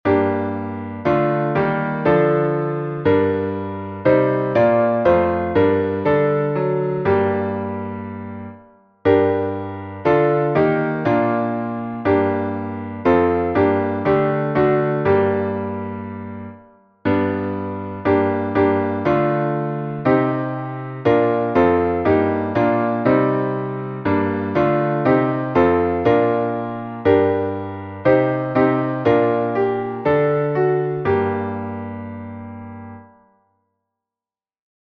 Modo: hipoeólio
salmo_110A_instrumental.mp3